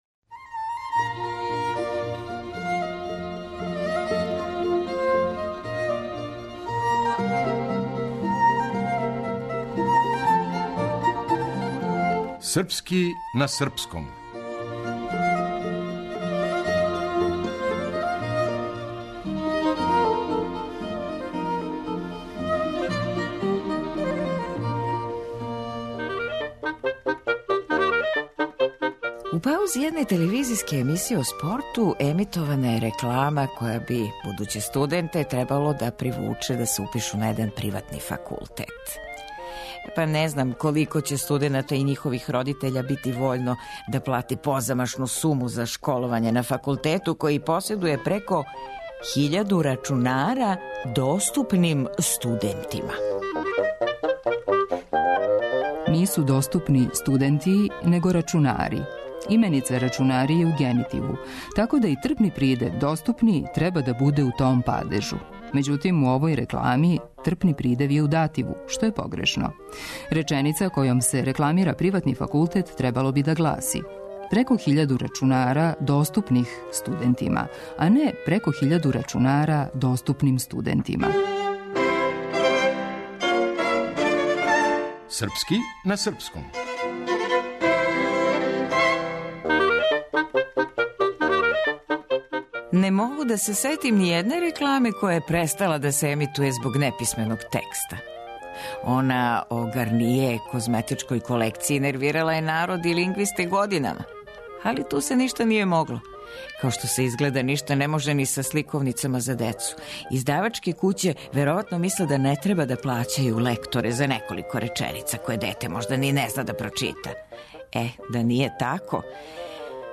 Драмски уменик